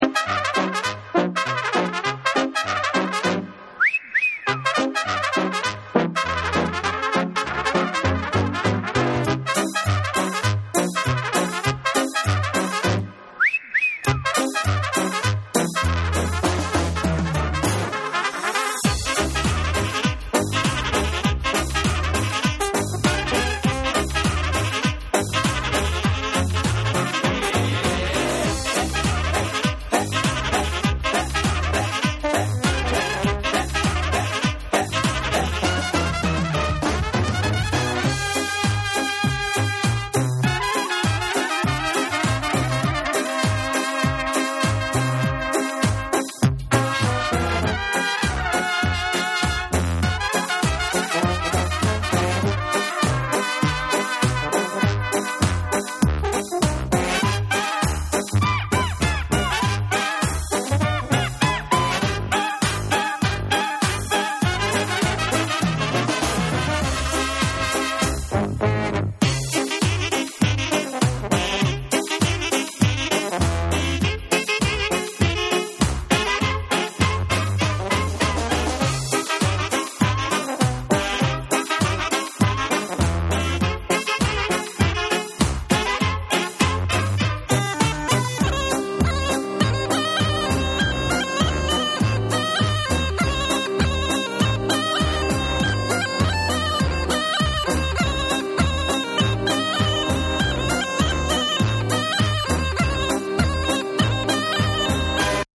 WORLD / NEW RELEASE